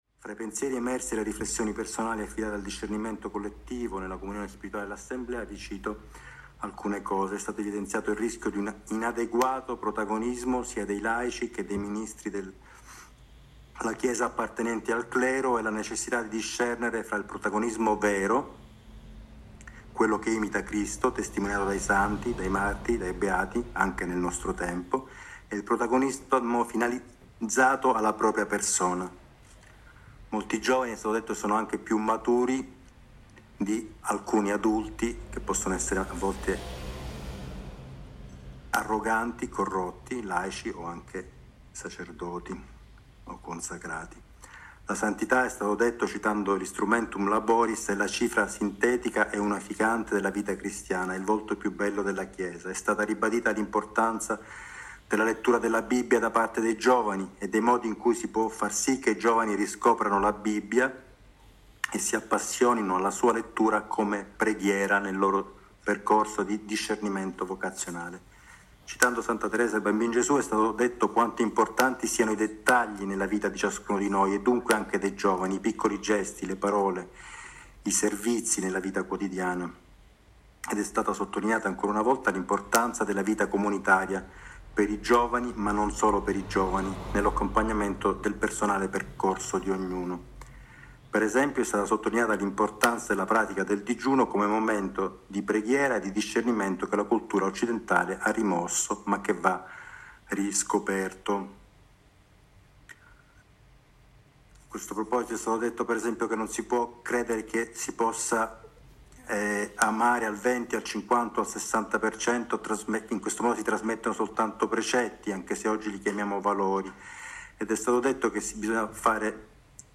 Stream audio estratto dal briefing del 18 ottobre
Briefing-18-ottobre-Ruffini.mp3